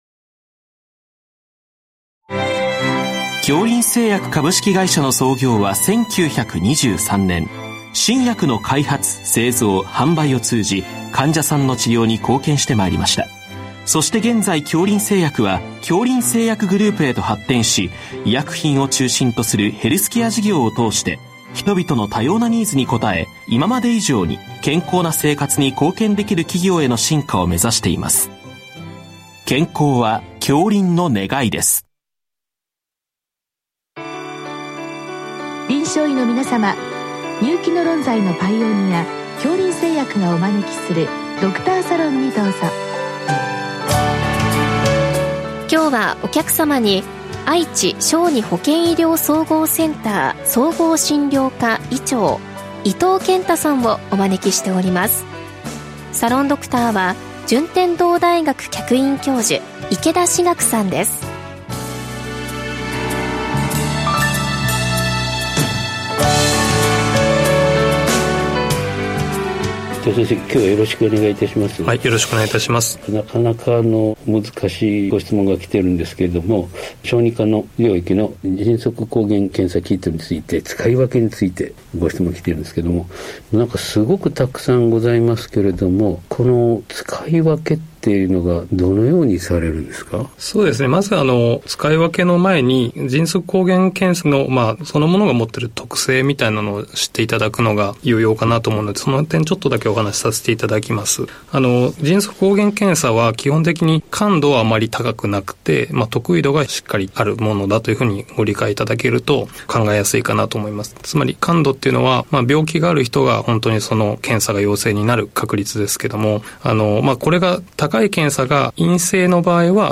全国の臨床医の方々にむけた医学情報番組。臨床医の方々からよせられたご質問に、各分野の専門医の方々にご出演いただき、解説いただく番組です。